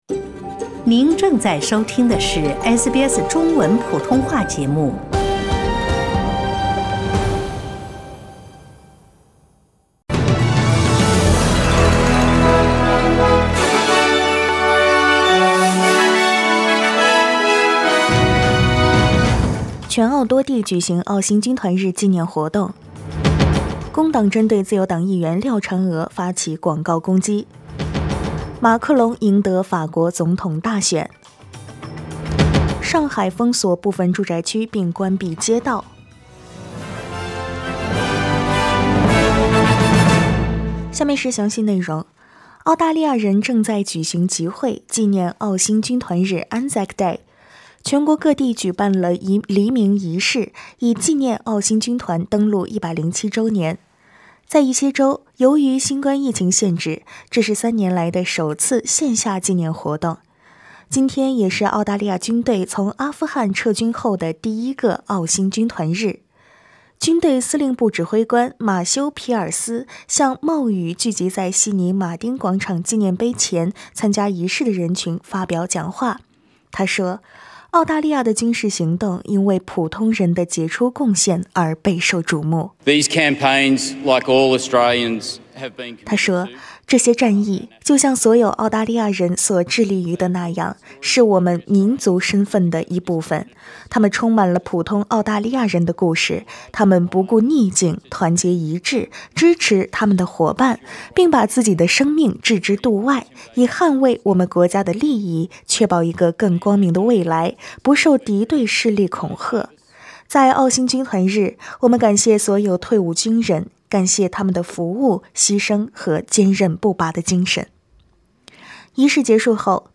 SBS早新闻（4月25日）
SBS Mandarin morning news Source: Getty Images